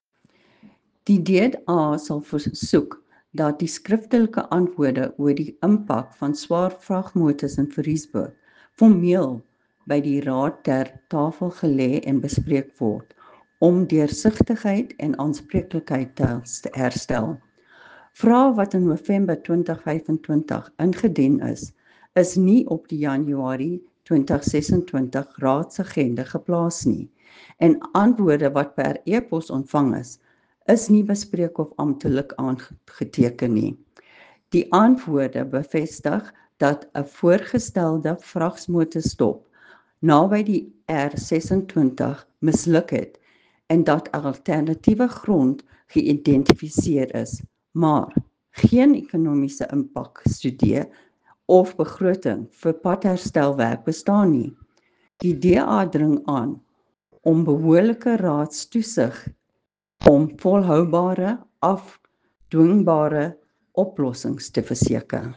Afrikaans soundbites by Cllr Irene Rügheimer and Sesotho soundbite by Jafta Mokoena MPL with a response from the mayor here